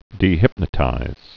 (dē-hĭpnə-tīz)